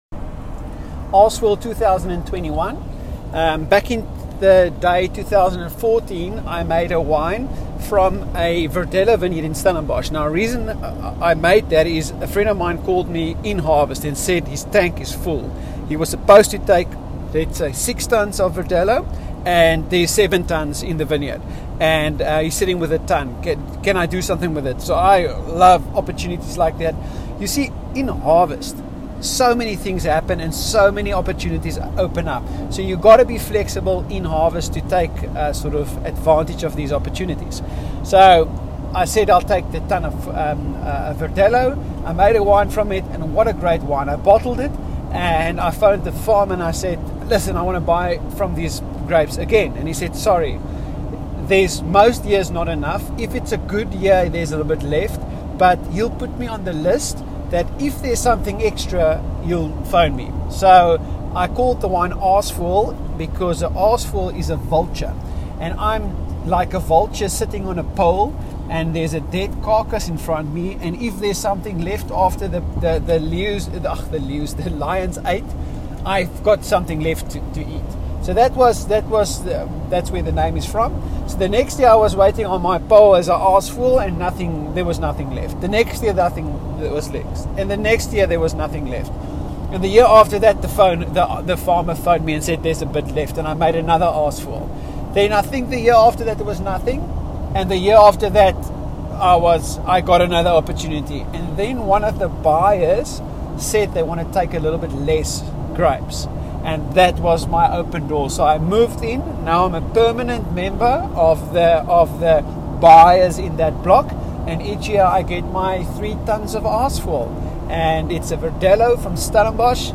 Voice Recording: